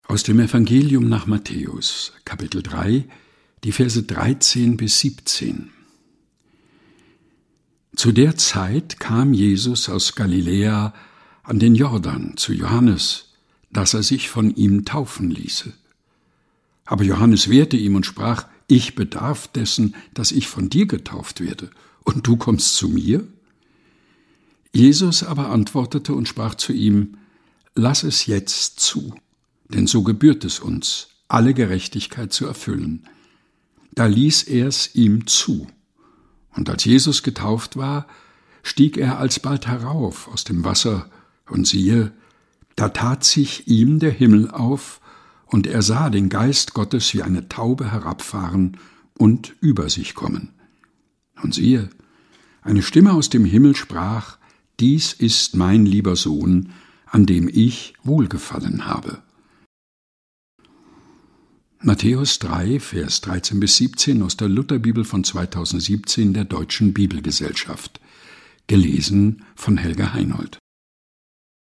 Predigttext zum 1. Sonntag nach Epiphanias.